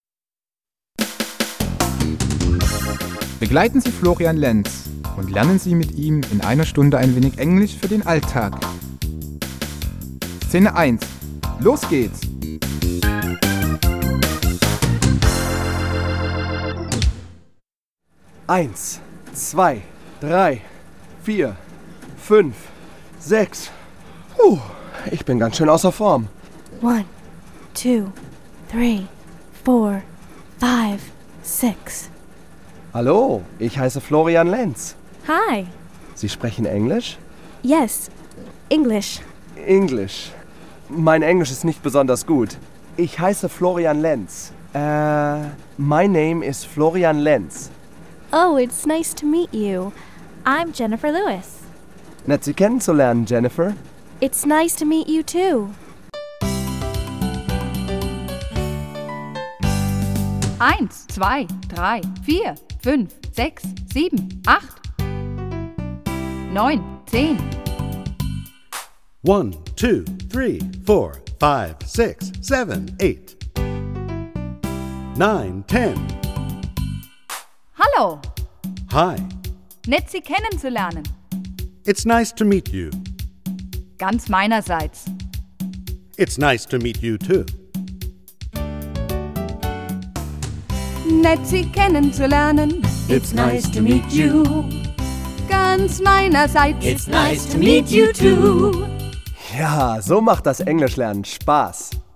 Ein musikalisches Sprachtraining